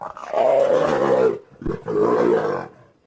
sounds_leopard_growl_02.ogg